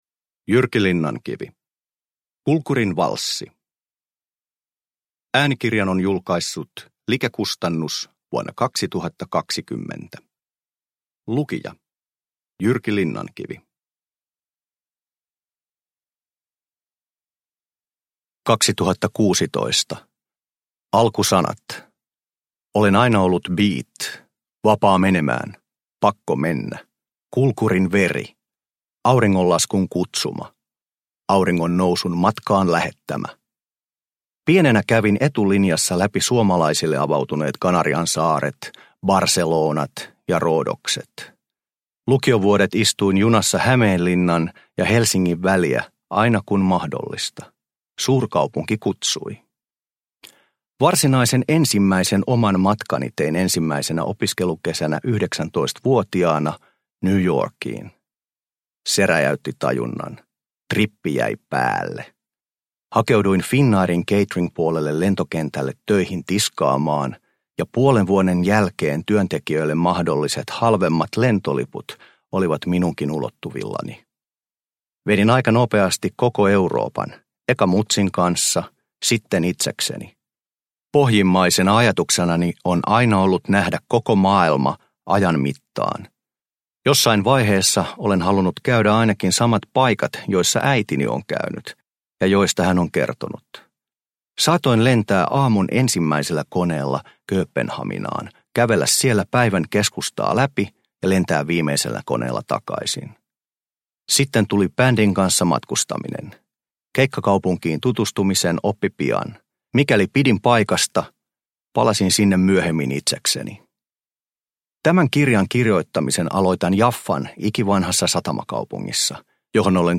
Kulkurin valssi – Ljudbok – Laddas ner
Uppläsare: Jyrki Linnankivi